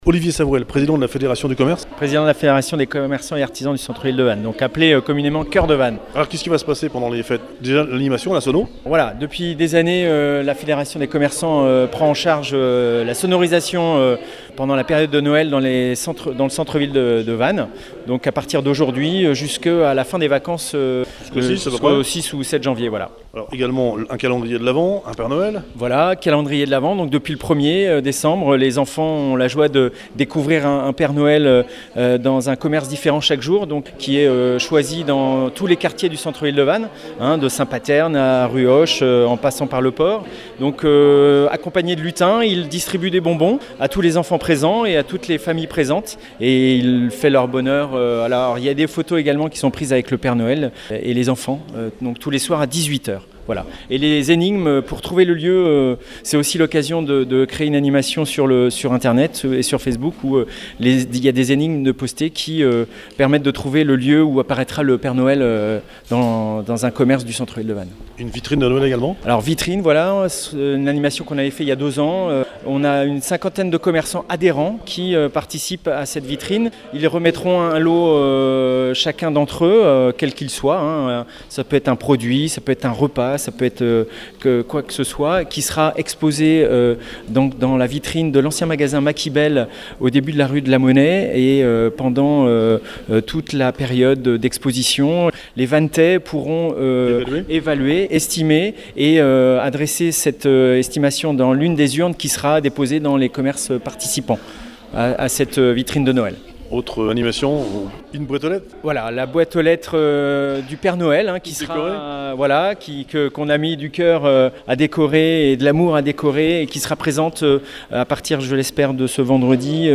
Présentation des Animations des Commerçants